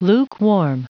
Prononciation du mot lukewarm en anglais (fichier audio)
Prononciation du mot : lukewarm